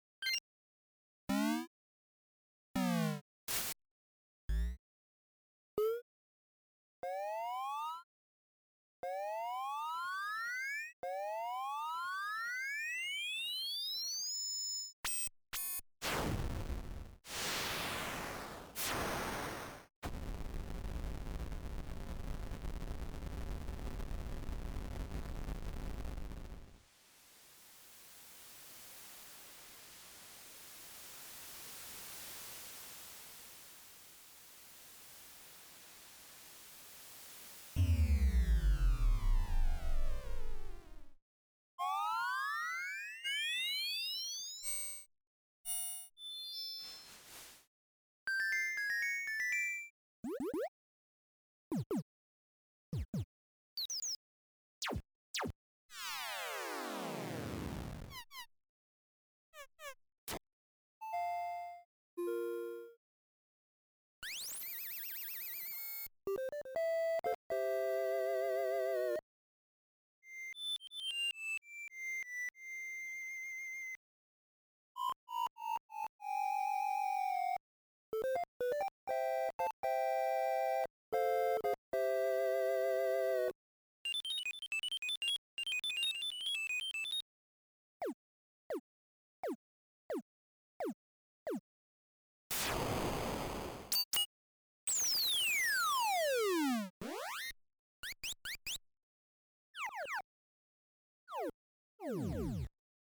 Custom original 8-Bit music and sound fx pack for your retro/indie game!
Sound FX: Coins, Jump Up, Fall, Sword Swipe, Blocked, Blocked 2, Elevator Lift 1, Elevator Lift 2, Elevator Lift 3, Open Door, Open Door 2, Explosion Explosion 2, Car Drive-By, Fire Sizzle, Water Wind & Waves, Down the Tunnel, Teleport, Teleport 2, Spring 1, Spring 2, Shiny Key, Rustling Trees & Bush, Treasure, Bubbly, Punch, Kick, Bird, Laser Gun, Rocket, Animal Cry, Animal Cry 2, POP!, Doorbell, Doorbell 2, New Item, Bed Time, Failure, Mission Complete, Text Bubble, Slow Walk, Fire Breather, Anvil, Heads Up!, STOP!, Squeaky, Enemy, Enemy Down, Big Boss